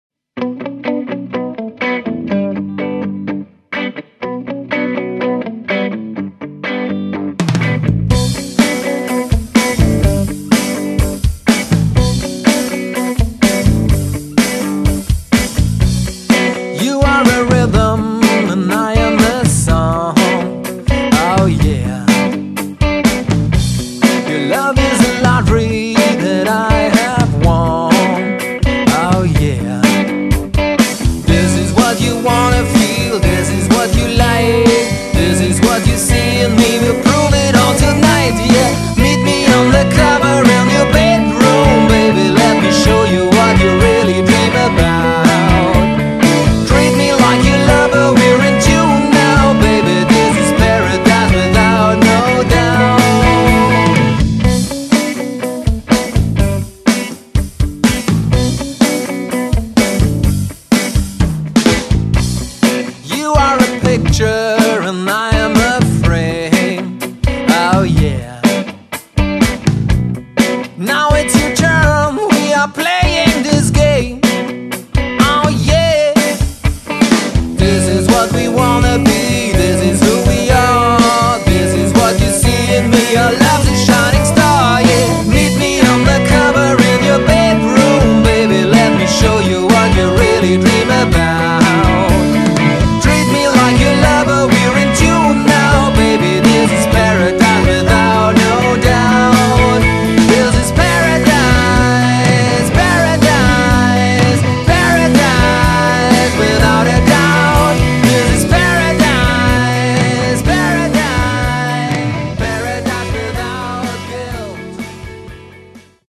Singer-Songwriter Pop Songs